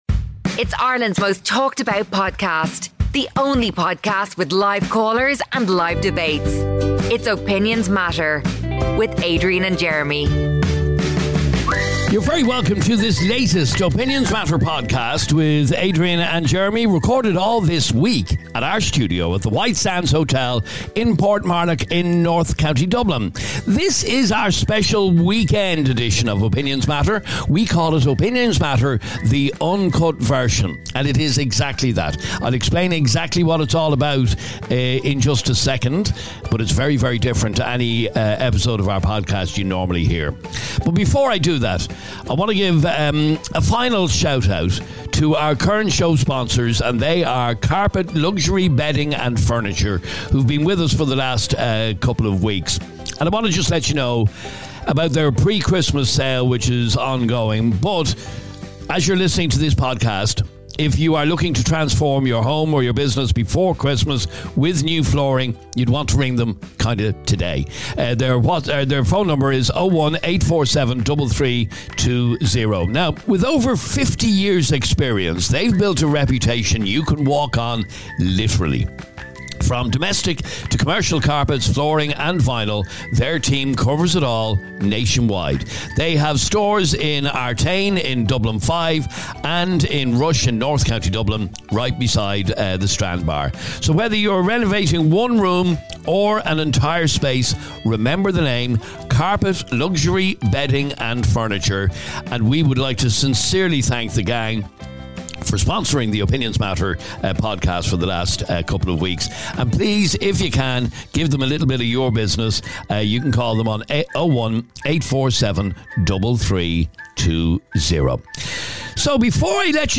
From a comical debate about fish and chips with mushy peas to the unforgettable moment of a seal pushing someone (you'll have to hear it to believe it!), this episode is jam-packed with laughter.